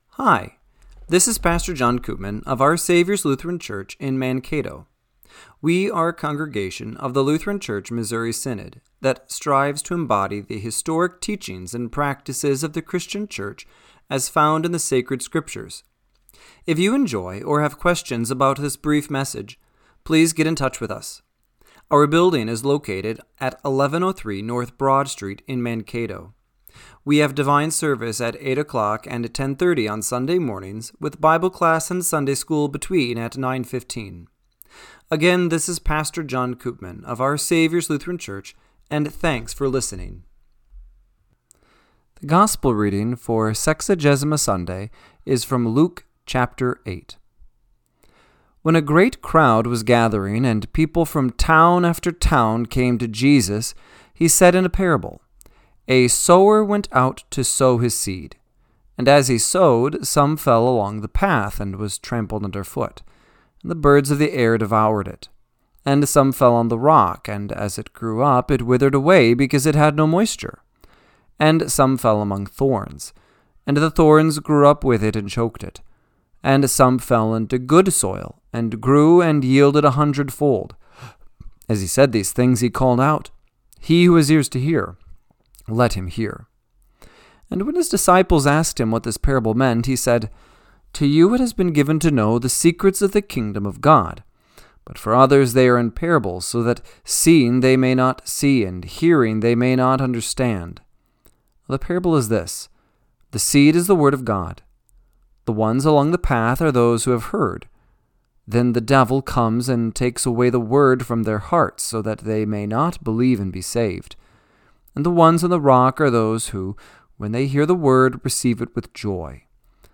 Radio-Matins-2-8-26.mp3